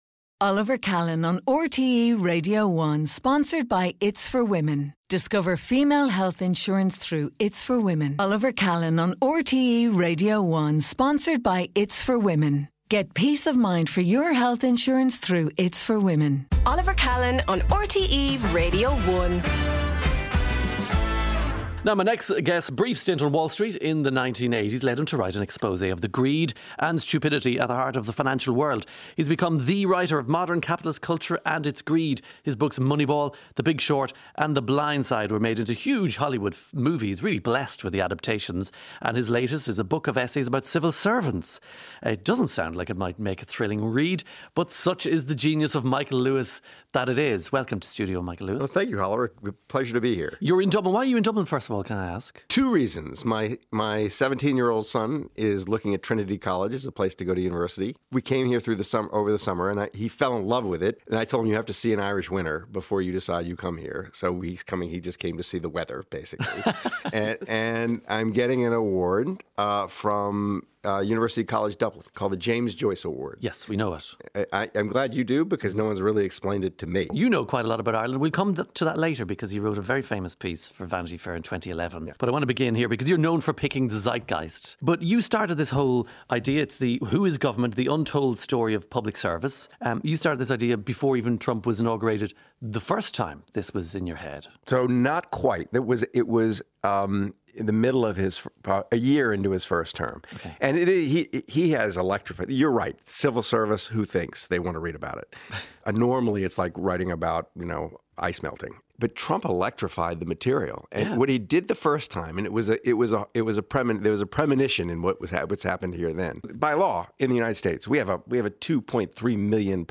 Oliver Callan charms the mood of the nation, discusses trending topics, the latest in sport, the arts and everything that's hopping in Irish life! Listen live Monday to Friday at 9am on RTÉ Radio 1.